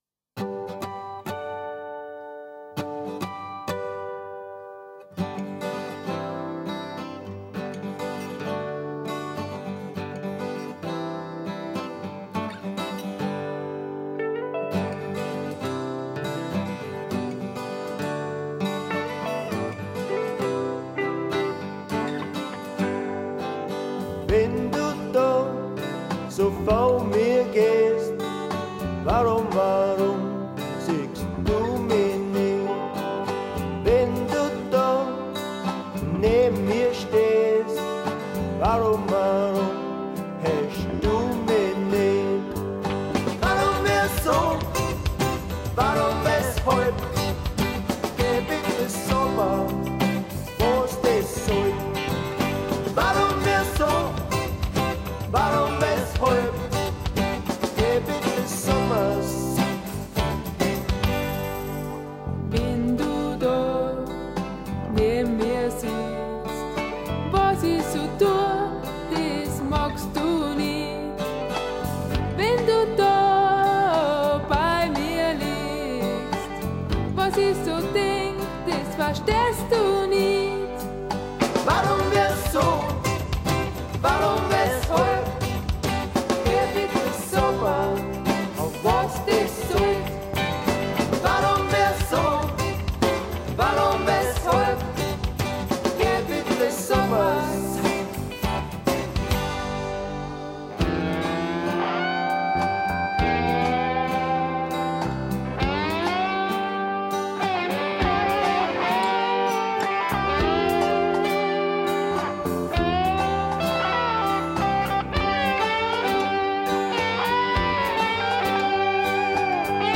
Man hört die wunderschöne Stimme